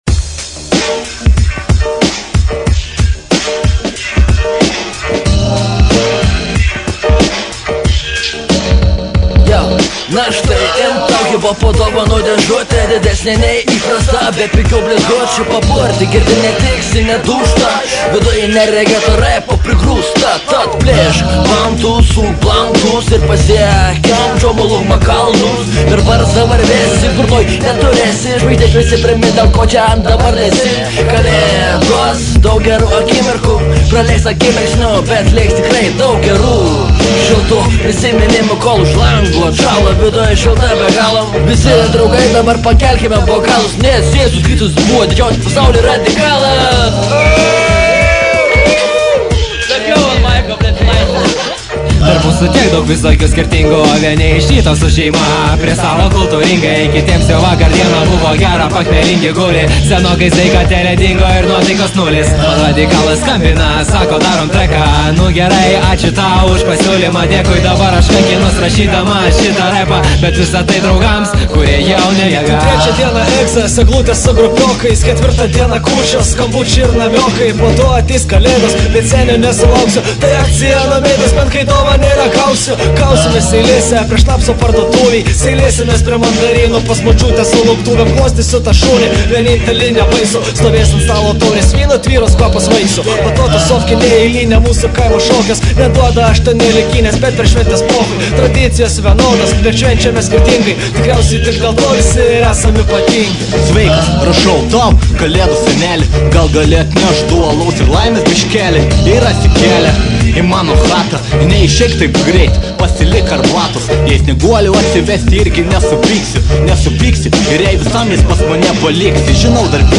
Kiti elfai kurie repavo
Beatbox